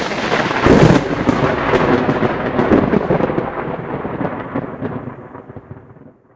lightning.ogg